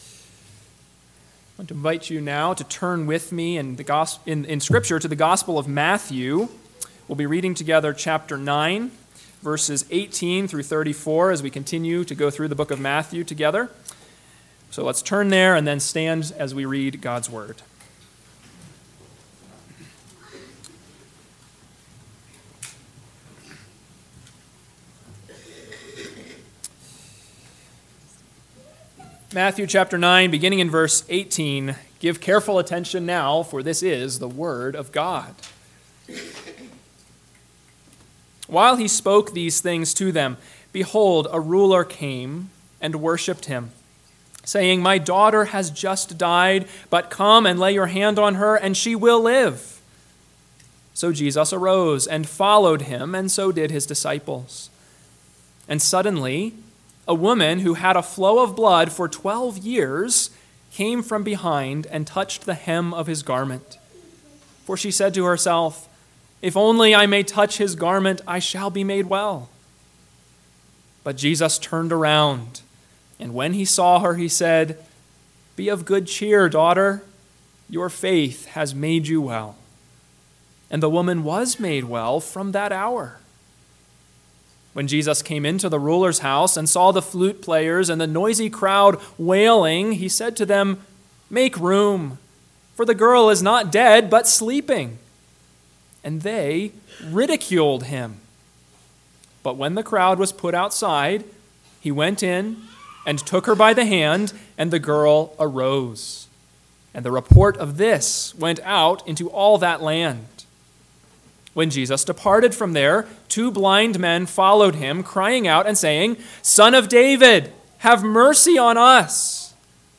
AM Sermon